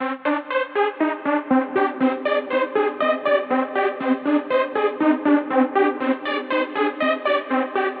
LOOP 1 120bpm
Tag: 120 bpm Trap Loops Guitar Electric Loops 1.35 MB wav Key : Unknown